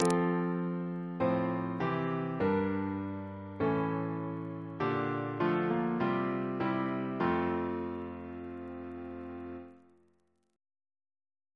CCP: Chant sampler
Single chant in F Composer: John Hindle (1761-1796) Reference psalters: ACB: 24; H1940: 616; H1982: S49; RSCM: 183